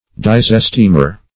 Disesteemer \Dis`es*teem"er\, n. One who disesteems.